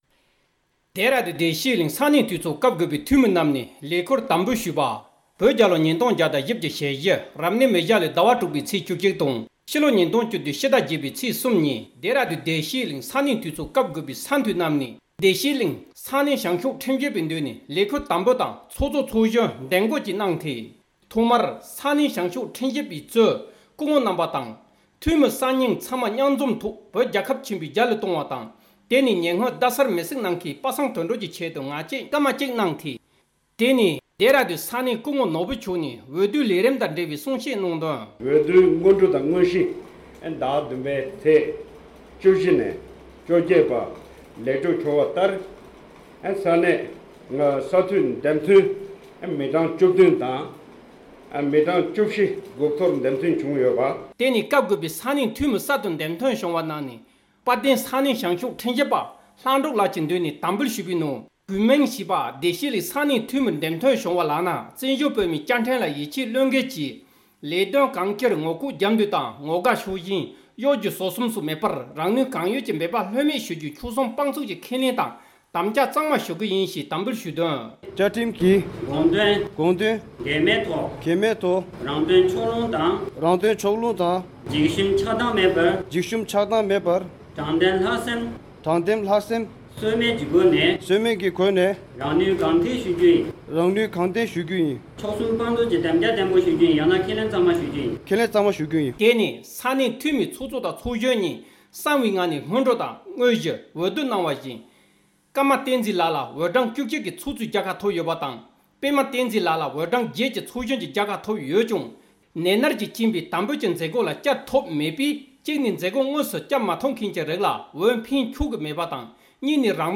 ས་གནས་ནས་གནས་ཚུལ་བཏང་འབྱོར་བྱུང་བར་གསན་རོགས༎